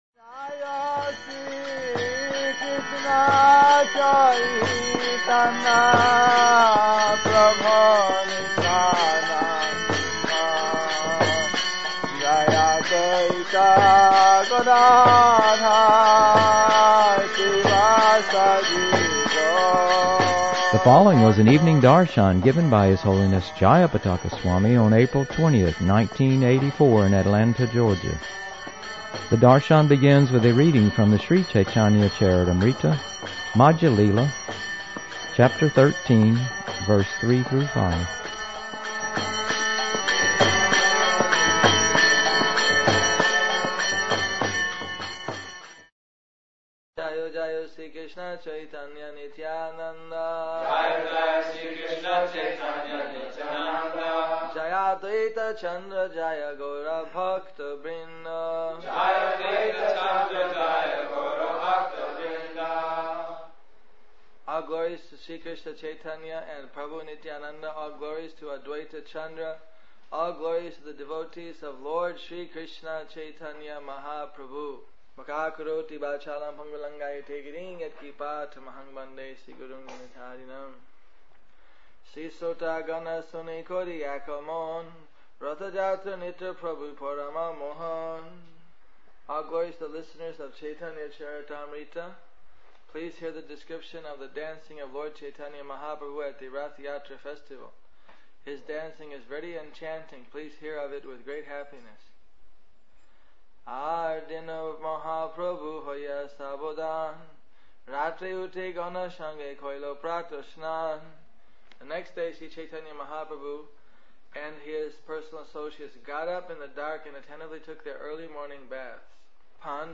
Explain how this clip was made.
The darsana begins with a reading from the Sri Caitanya-Caritamrta, Madhya-lila, Chapter 13, Verse 3-5